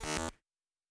pgs/Assets/Audio/Sci-Fi Sounds/Electric/Glitch 1_01.wav at master
Glitch 1_01.wav